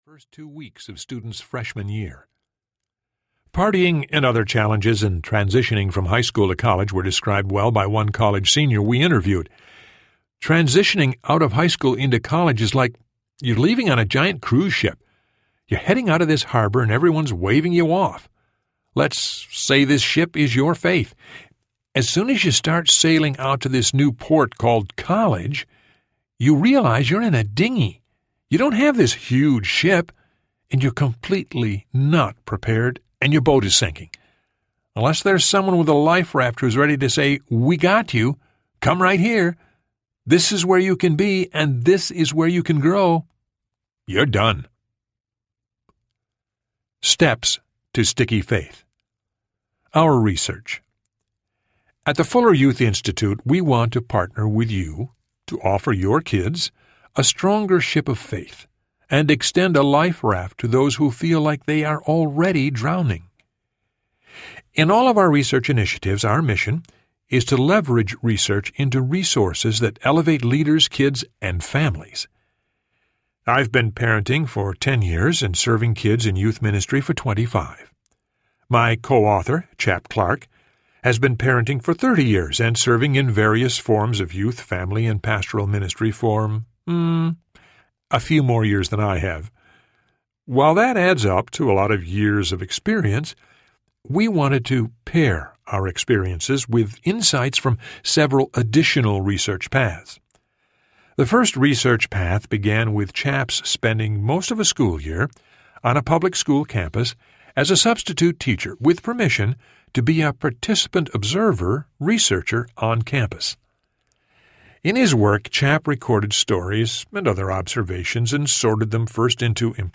Sticky Faith Audiobook
Narrator
5.8 Hrs. – Unabridged